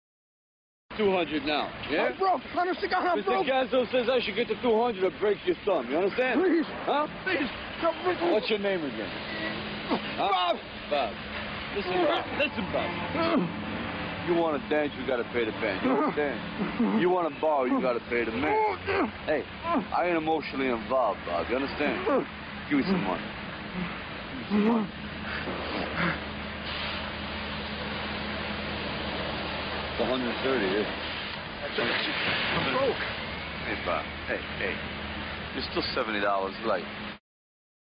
在线英语听力室影视剧中的职场美语 第58期:束手无策的听力文件下载,《影视中的职场美语》收录了工作沟通，办公室生活，商务贸易等方面的情景对话。